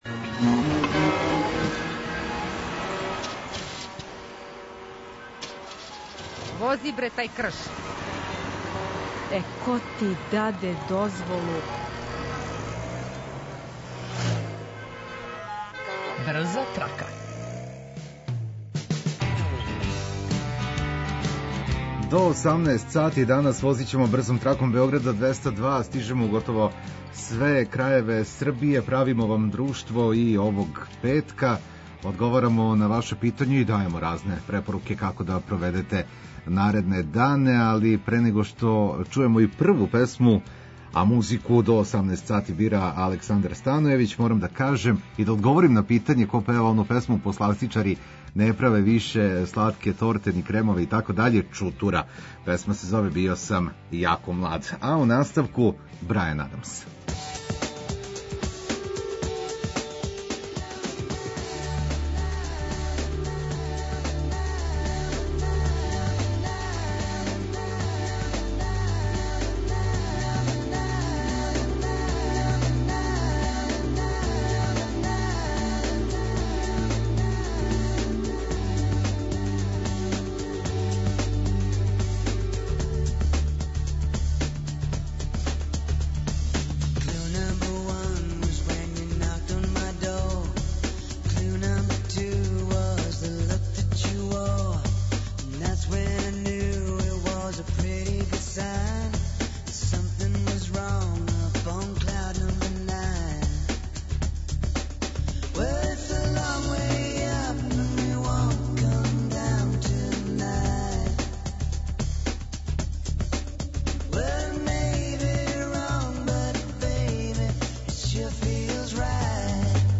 Ту је и одлична музика која ће вам олакшати сваки минут, било где да се налазите.